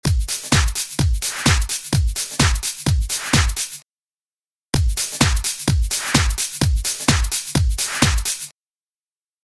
Tip 4: Compressing Your Drum Buss
The PSP Vintage Warmer is a popular choice for this application, as are the Waves compressors, but for this example I have used the Cubase Vintage Compressor, aiming for just 2-3 dB of gain reduction:
Again, the effect is subtle but that little extra punch and definition helps bring the beat together and should help it sit better within your mix.